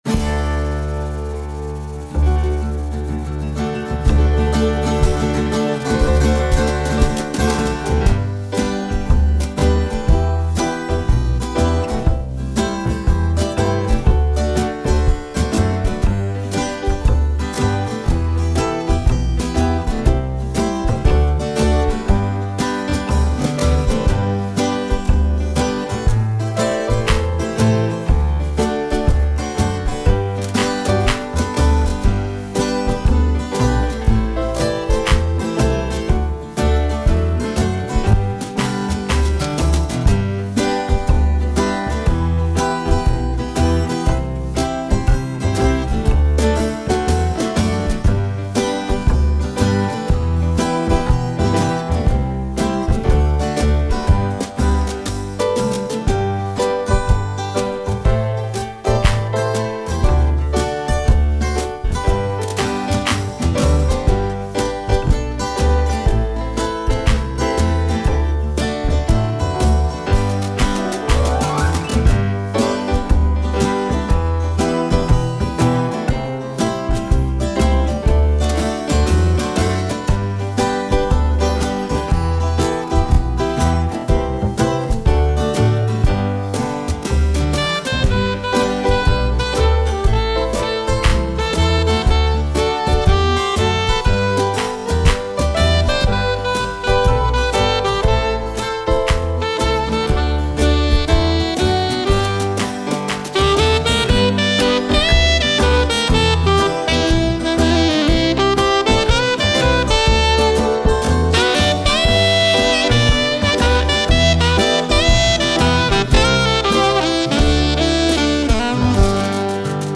MeinLiederschatz_pb G-Dur.mp3